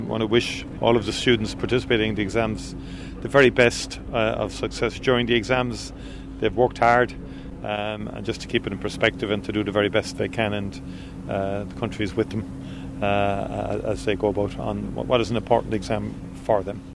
The Taoiseach Micheál Martin has this message for anyone starting their exams today: